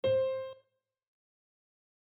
C_DO.mp3